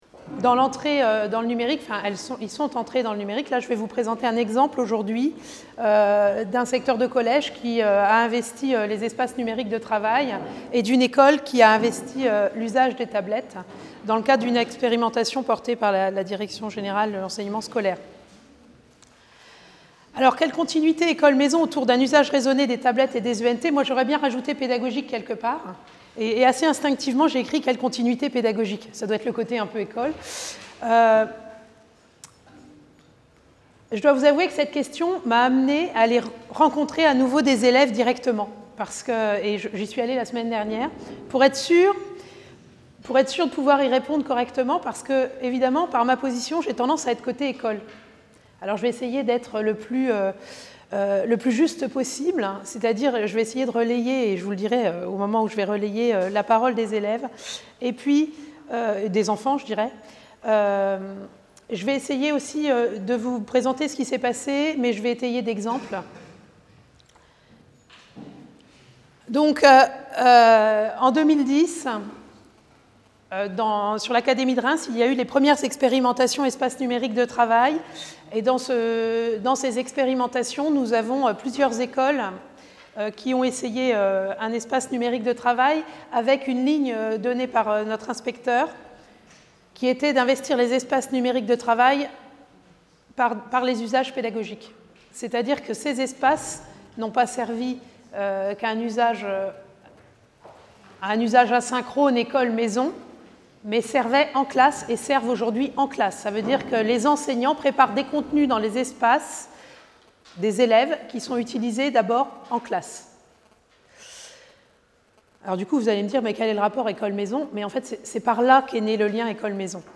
Journée d’étude organisée par l’EHESS en collaboration avec le ministère de l’éducation nationale 8 avril 2014, Salle des Conférences, Lycée Henri IV, 75005 Paris Maintenant, les objets communicants font partie de l’environnement des enfants dès leur naissance. Si ces objets ne sont pas entre les mains des jeunes enfants, ils sont omniprésents chez leurs entourages et dans le monde matériel et culturel qui les entoure.